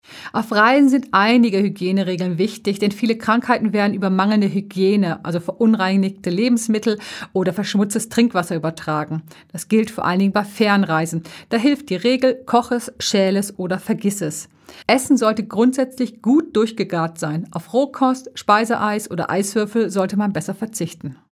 O-Töne25.05.2023